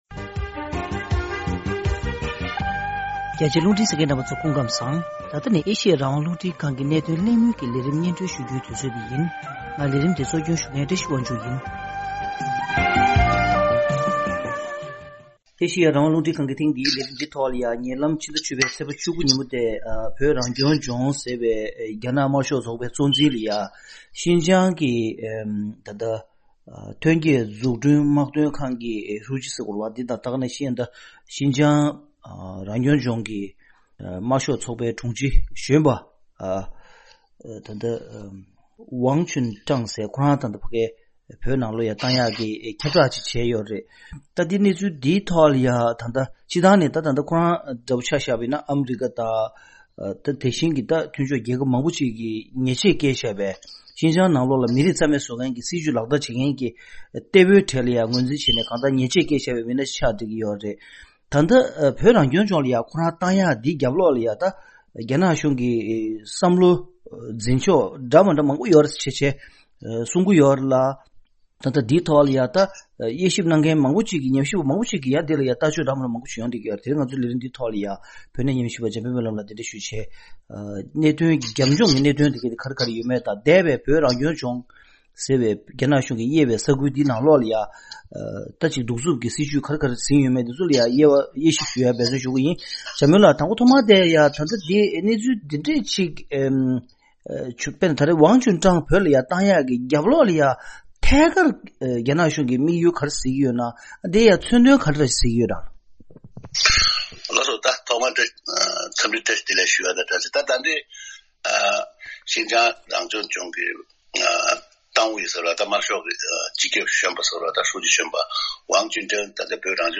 རྒྱ་ནག་གིས་བོད་རང་སྐྱོང་ལྗོངས་ཟེར་བའི་ནང་འགོ་ཁྲིད་གསར་པ་བསྐོ་བཞག་བྱས་ཏེ་བོད་ནང་སྔར་ལས་ལྷག་པའི་དམ་དྲག་གི་སྲིད་བྱུས་བྱ་འཆར་ཡོད་མེད་ཐད་གླེང་མོལ་ཞུས་པ།